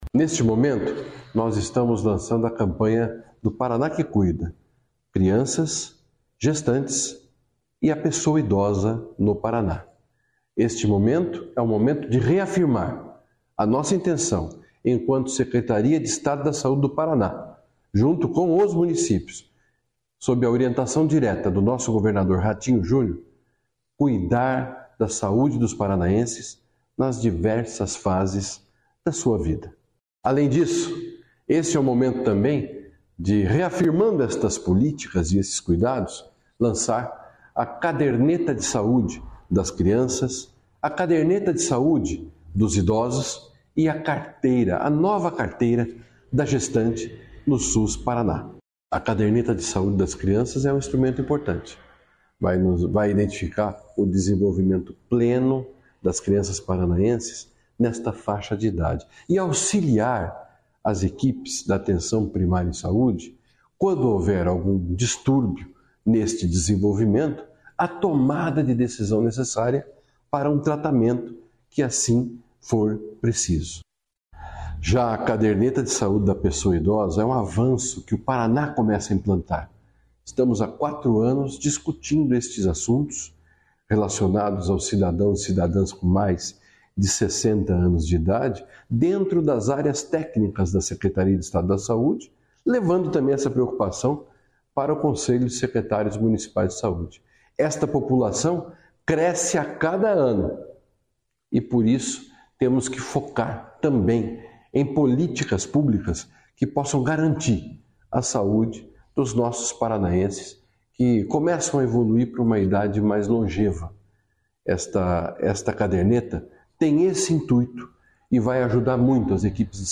Sonora do secretário da Sáude, Beto Preto, sobre o lançamento da caderneta de saúde